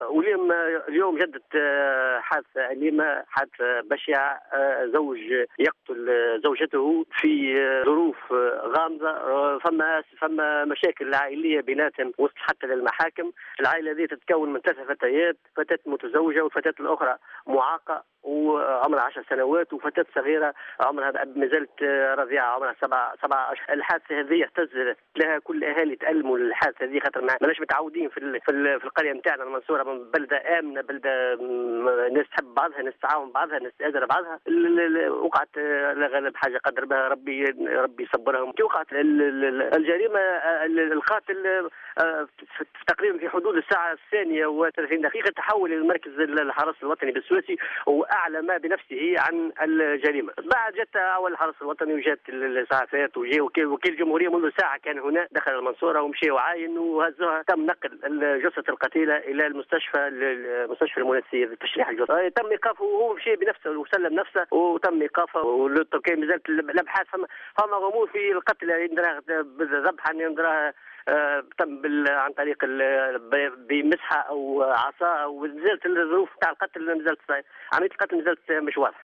شاهد عيان